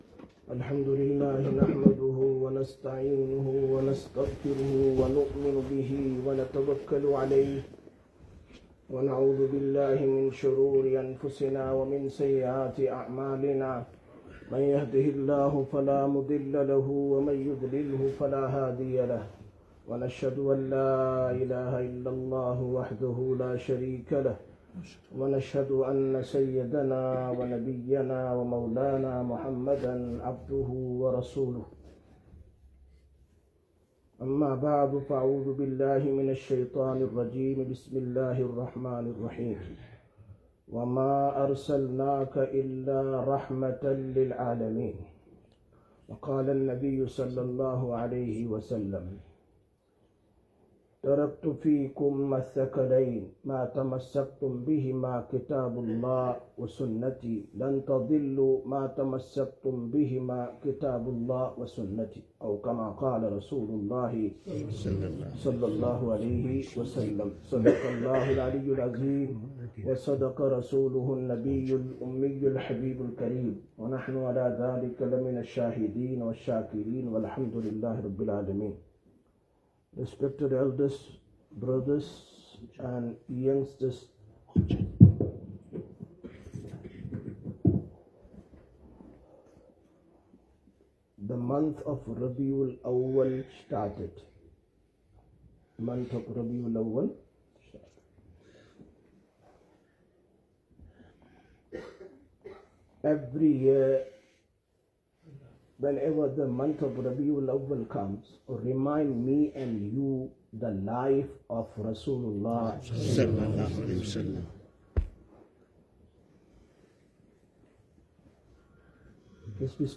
29/08/2025 Jumma Bayan, Masjid Quba